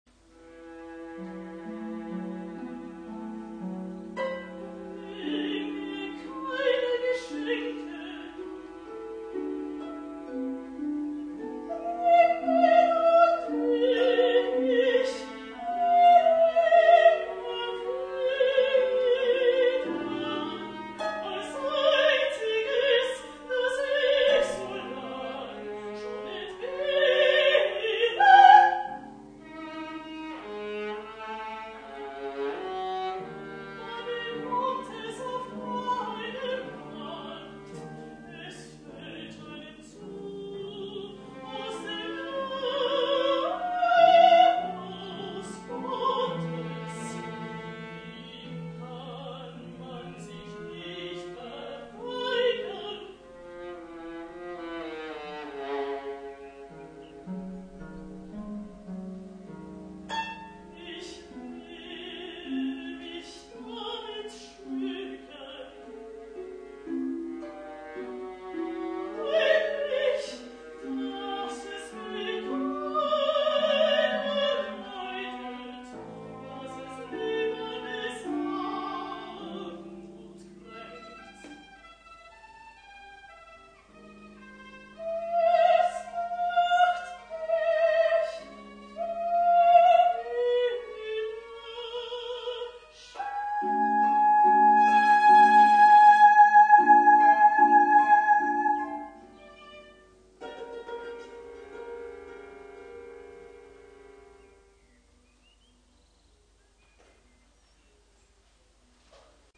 Sopran-Arie